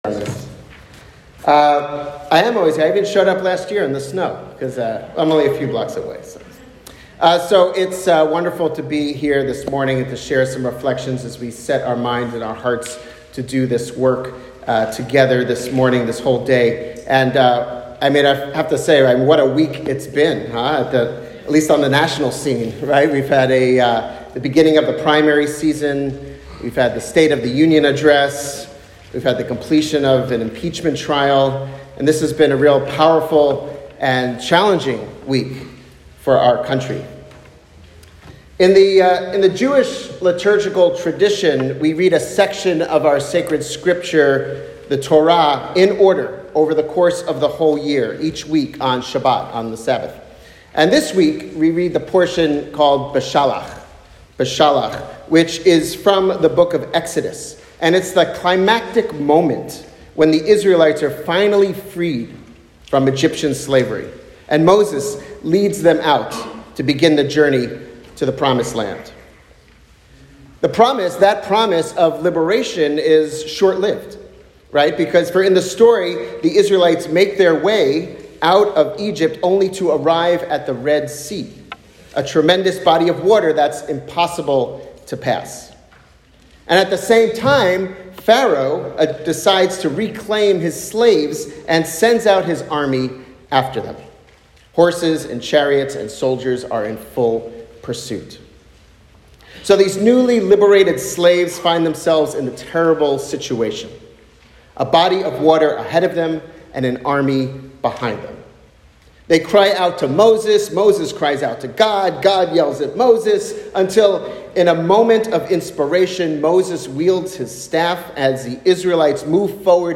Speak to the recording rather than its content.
On Thursday I was invited to give an interfaith spiritual reflection as part of Interfaith Advocacy Day, sponsored by Faith Action Network: